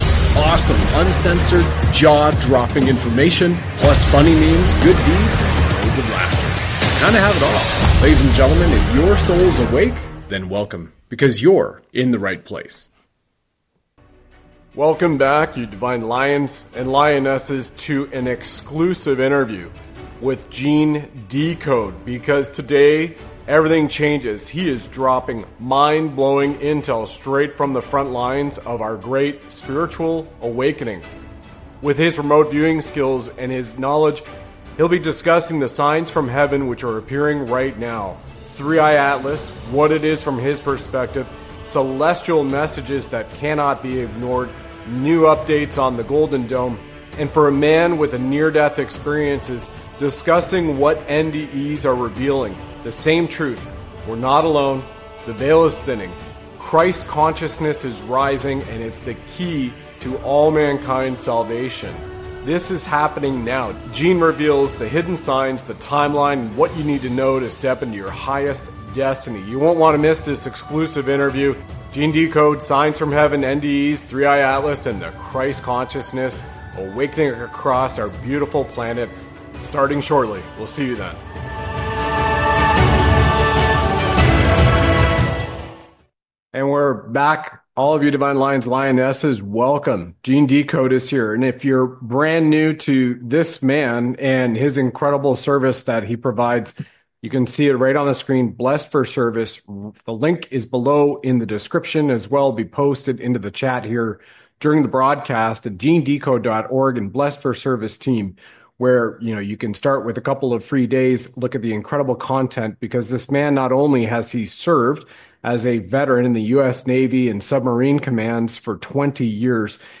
This text is about an interview